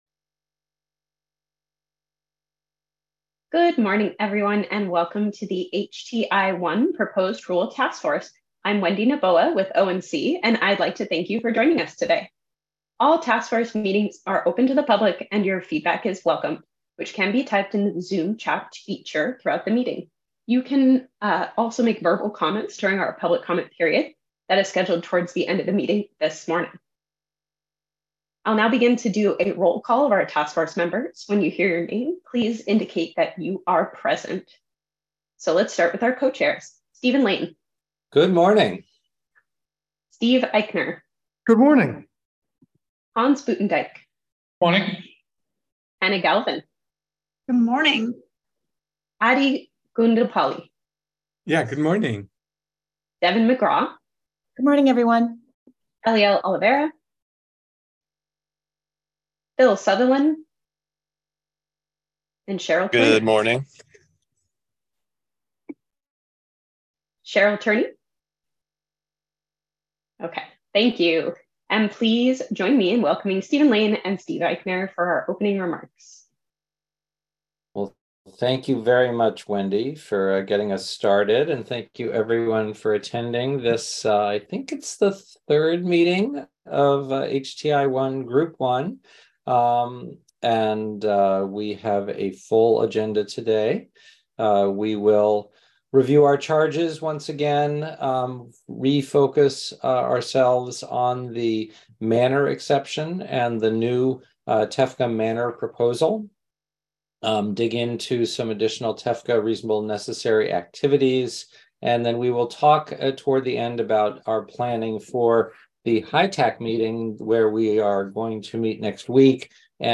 HTI-1 Proposed Rule Task Force Group 1 Meeting Audio 5-9-2023